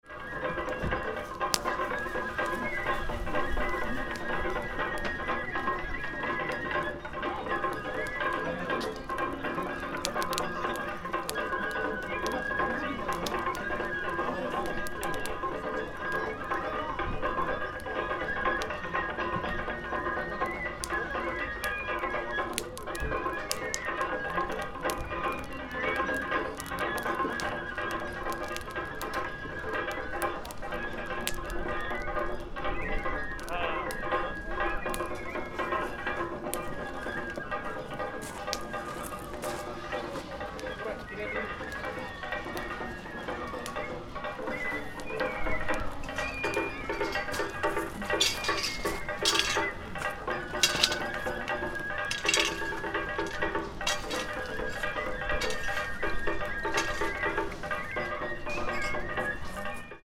Gokoku Shrine
Don-do Yaki festival was held at Gokoku Shrine as usual year. Don-do Yaki festival is a new year event that new year's ornaments and old talismans are burned.
Like last year, many worshippers stayed around the fire only briefly, although worshippers visited the shrine ceaselessly.
Recorded Kagura music was played through PA Speakers, similar to usual year.